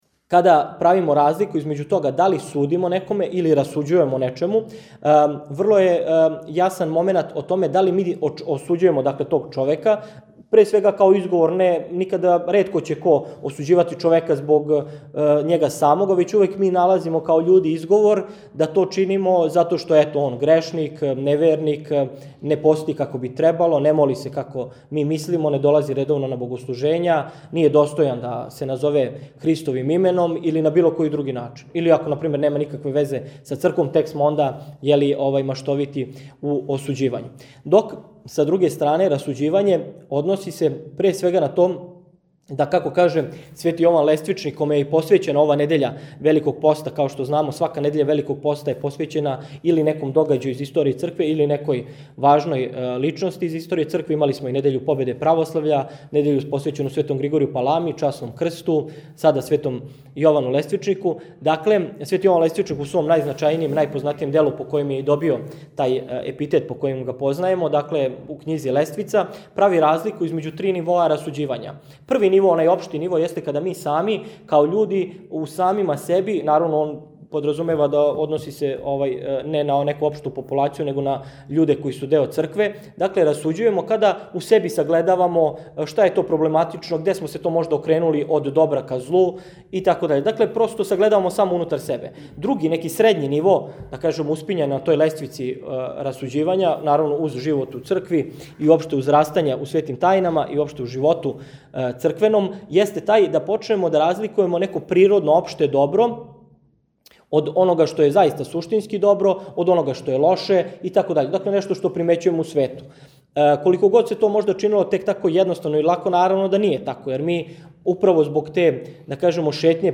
У понедељак, 31. марта 2025. године, са благословом Његовог Високопреосвештенства Митрополита шумадијског Г. Јована, одржани су двадесети по реду разговори о вери – Упознајмо православље.